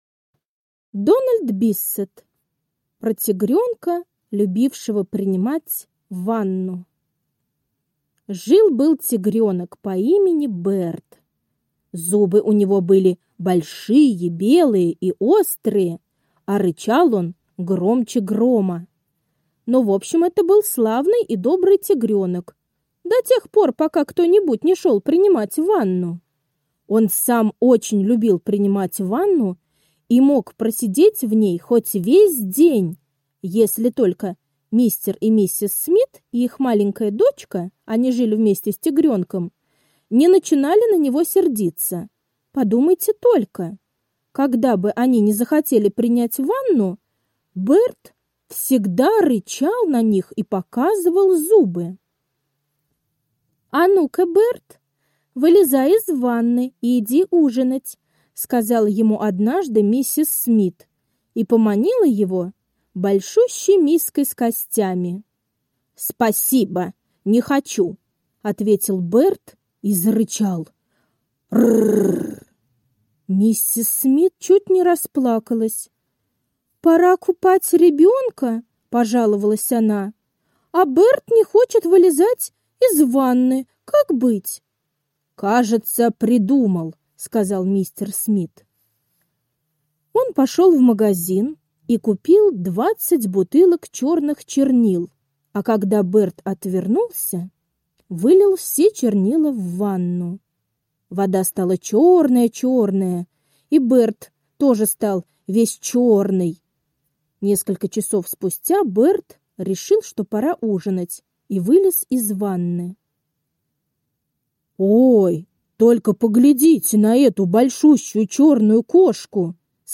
Про тигренка, любившего принимать ванну — аудиосказка Биссета Д. Тигренок любил долго принимать ванну.